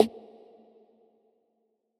SNARE - HALF HEART.wav